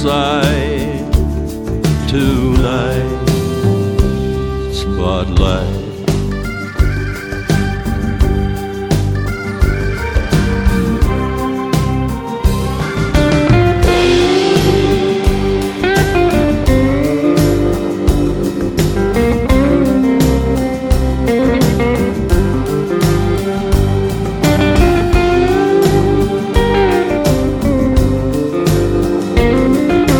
Guitare
country music